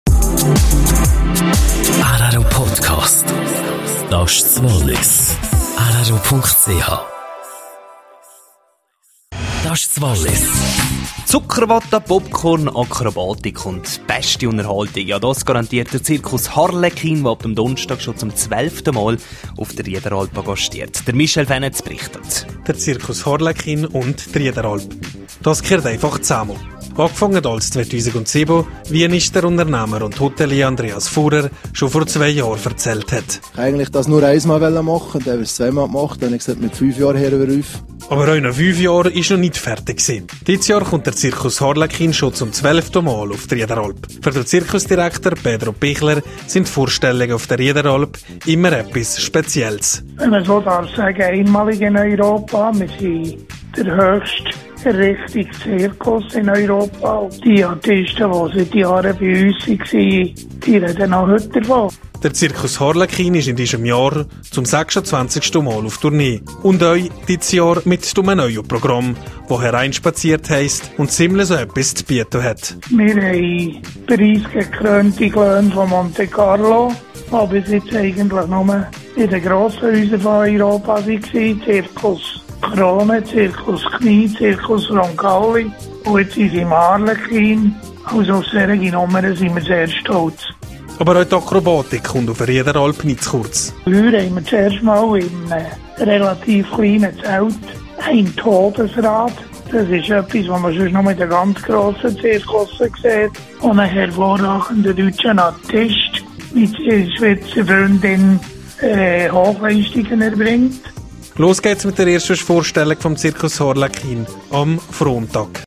Im rro-Interview